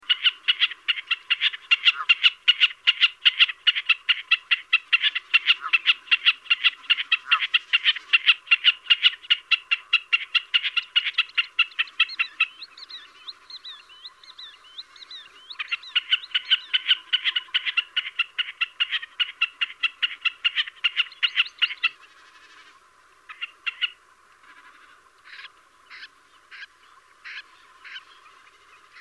Bekassine
Hören Sie sich hier den Gesang der Bekassine an.